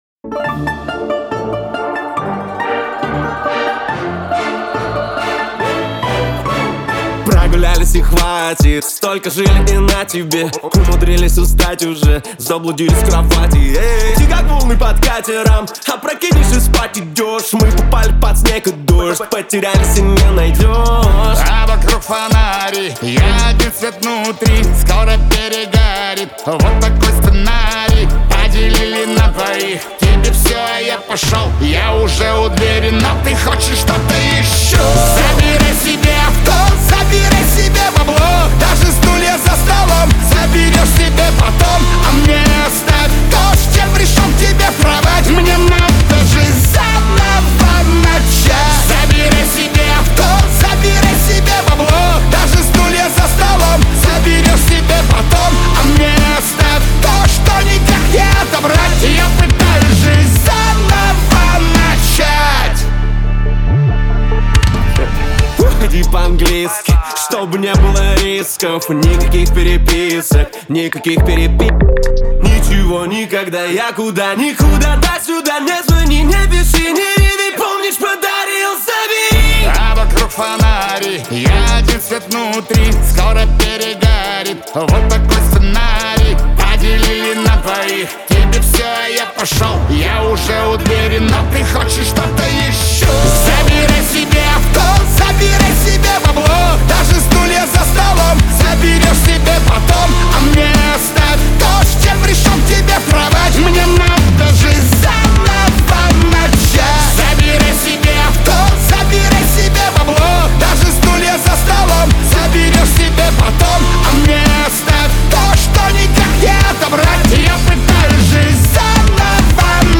Шансон
эстрада , дуэт